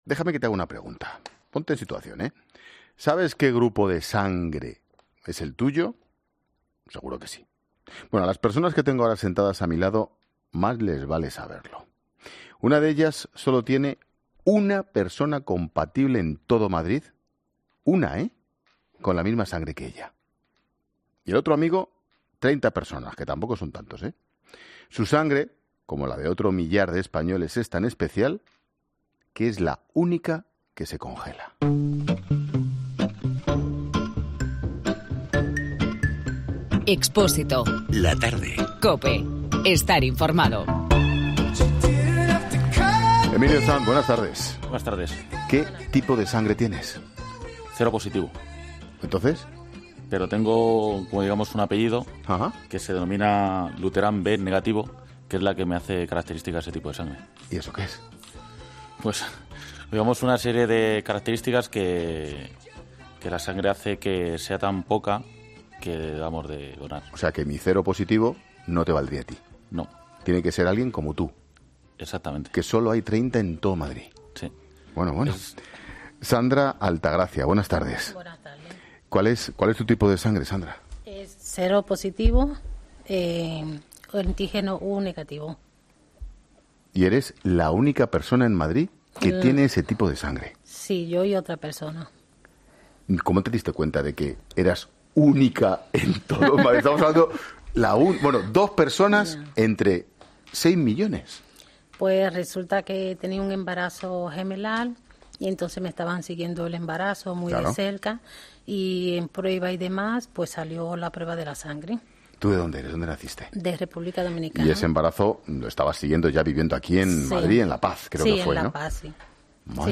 en el estudio de 'La Tarde'